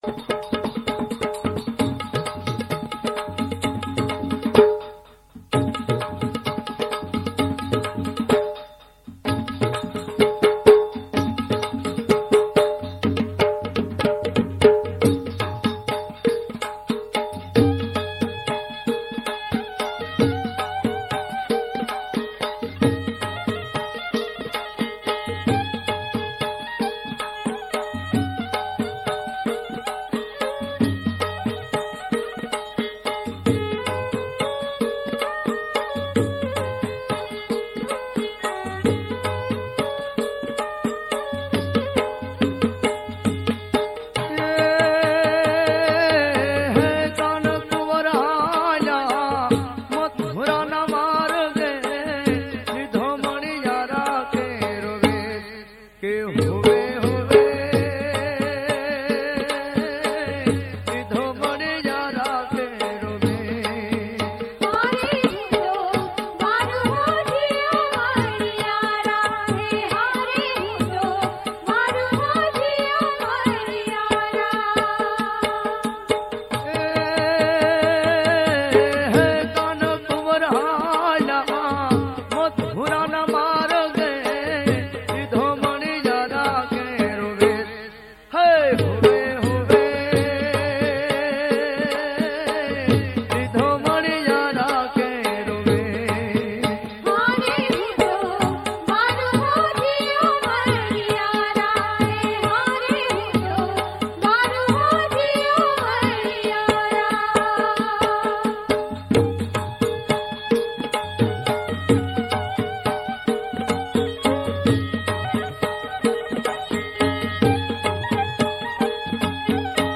POPULAR KRISHNA LOKGEET